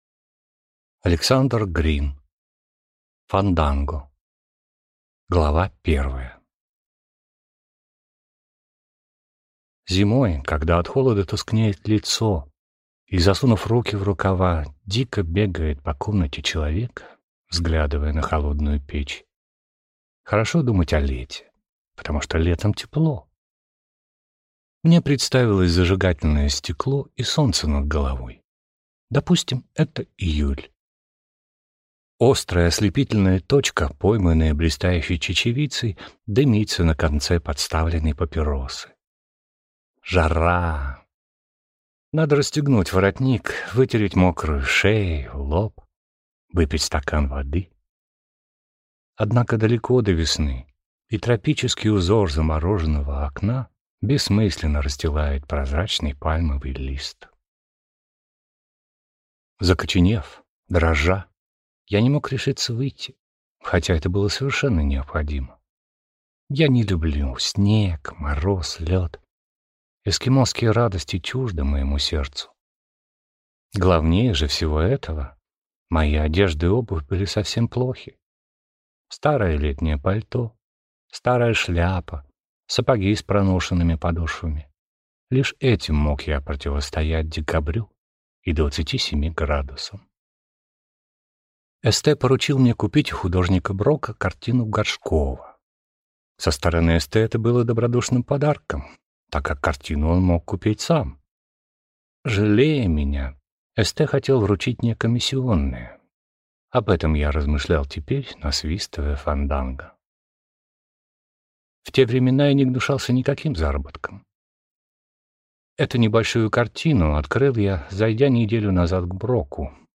Аудиокнига Фанданго | Библиотека аудиокниг